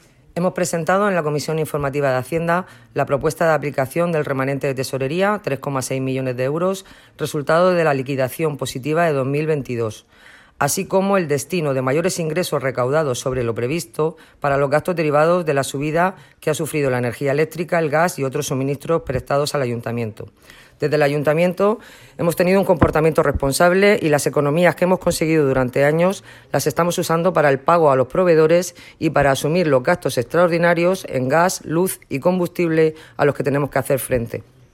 Enlace a Declaraciones de Esperanza Nieto sobre la Comisión de Hacienda